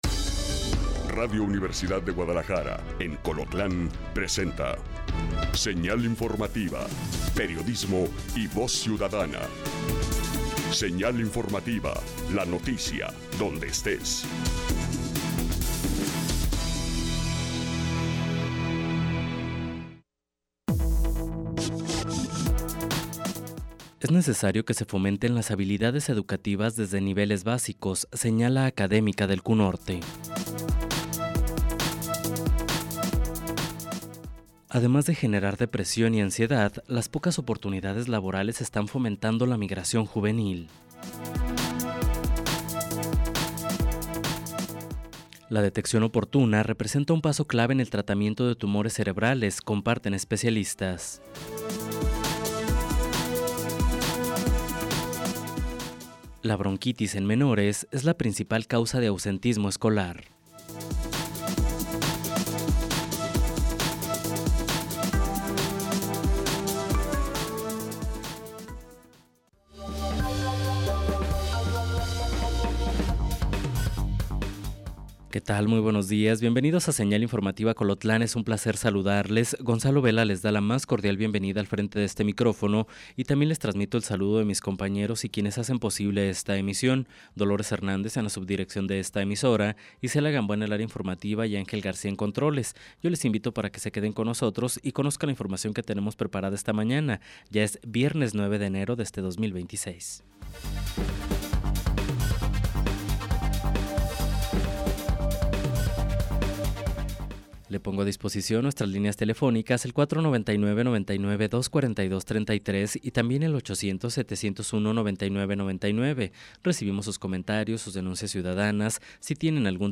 En este noticiero, nos enfocamos en las noticias locales que afectan directamente su vida y su entorno. Desde políticas y eventos comunitarios hasta noticias de última hora y reportajes especiales.